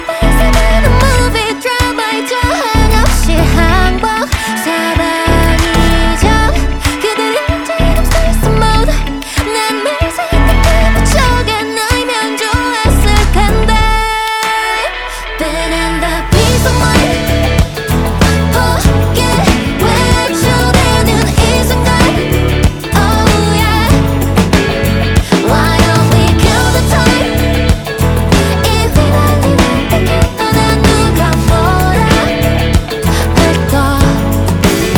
# Korean Rock